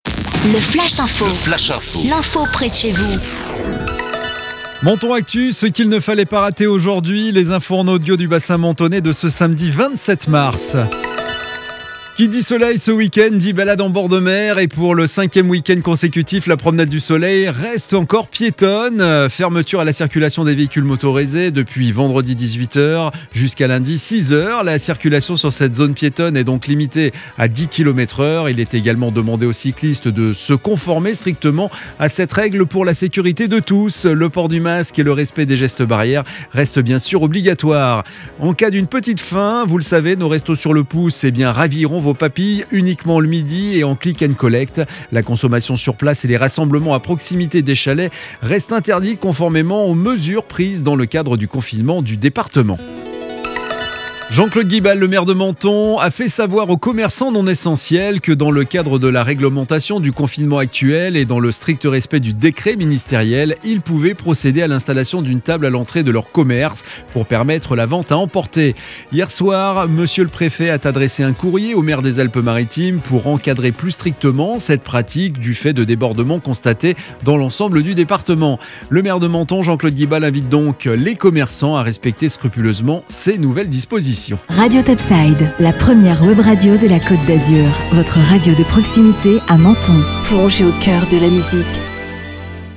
Menton Actu - Le flash info du samedi 27 mars 2021